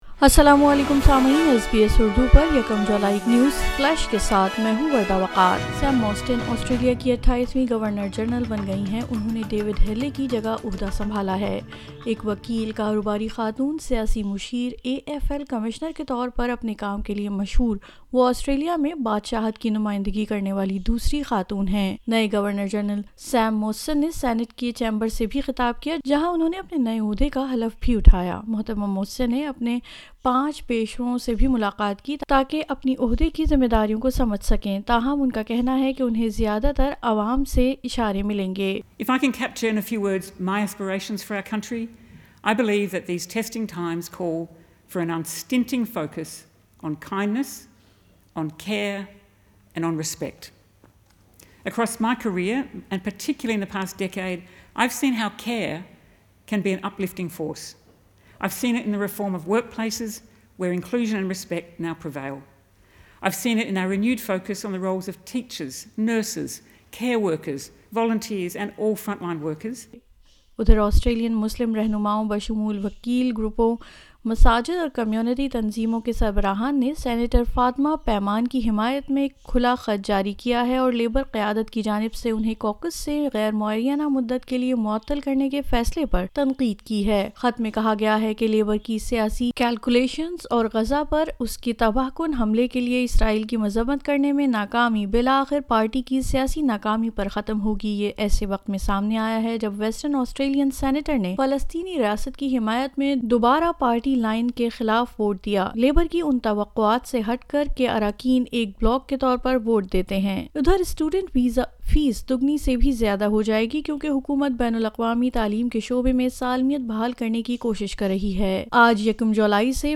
نیوز فلیش 01 جولائی 2024: آسٹریلیا کی نئی گورنر جنرل نے حلف اٹھا لیا